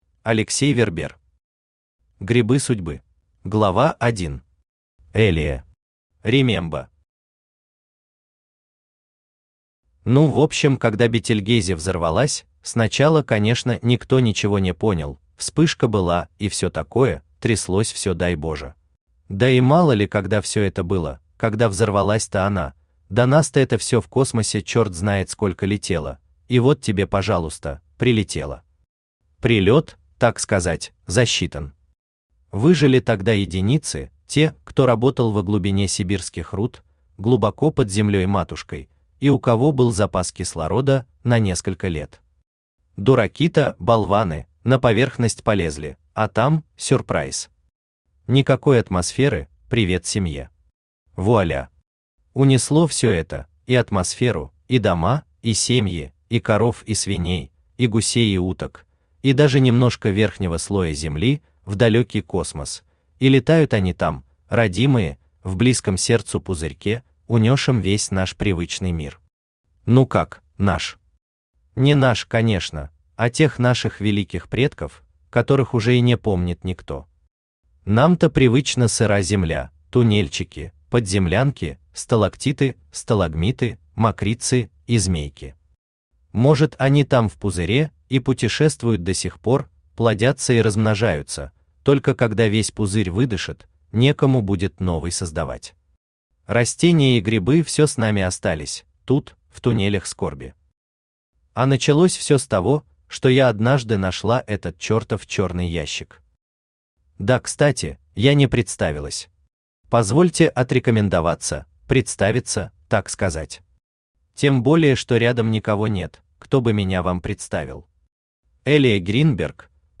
Аудиокнига Грибы судьбы | Библиотека аудиокниг
Aудиокнига Грибы судьбы Автор Алексей Вербер Читает аудиокнигу Авточтец ЛитРес.